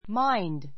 mind 中 A1 máind マ イン ド 意味map 名詞 ❶ 心, 精神 ❷ 考え, 意向, 意見 動詞 ❶ 疑問文や否定文で 気にする ❷ ～に気をつける 名詞 複 minds máindz マ イン ヅ ❶ 心 , 精神 ; 理性 mind and body 関連語 mind and body 精神と肉体, 心身 A good idea came into his mind.